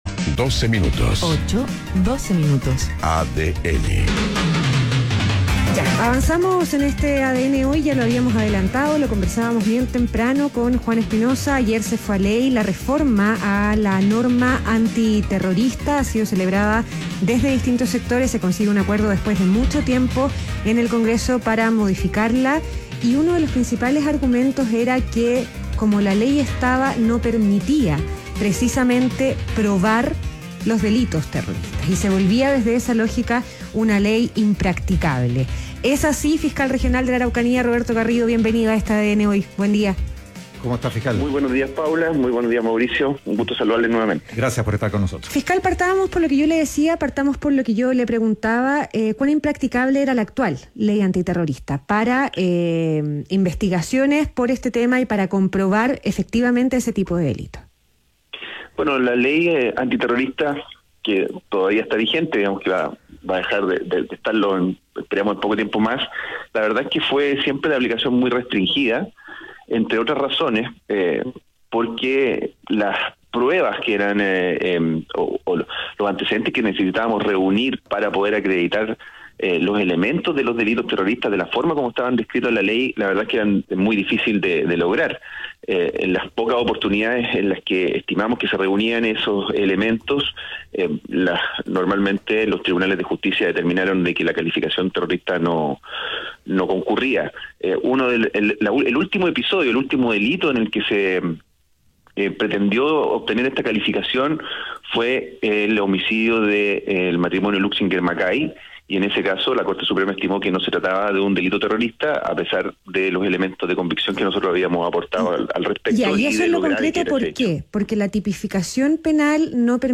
ADN Hoy - Entrevista a Roberto Garrido, fiscal regional de La Araucanía